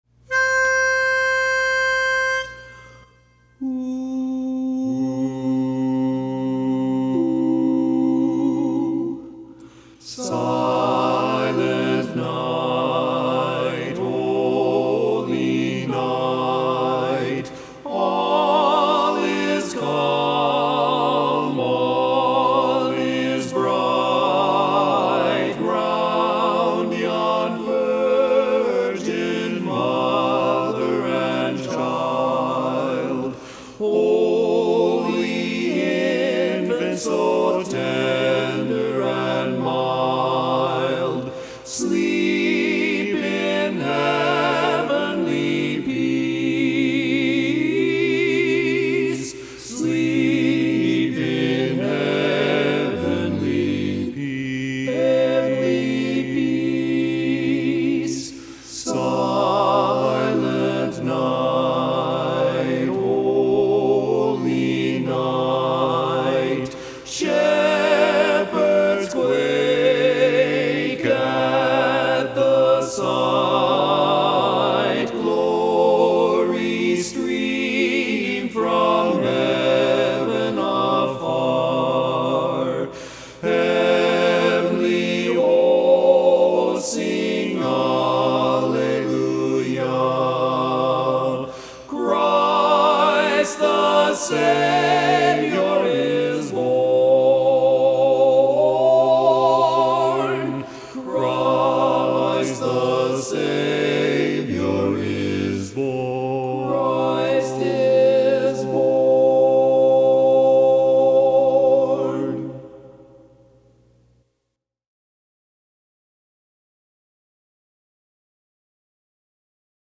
Barbershop
Lead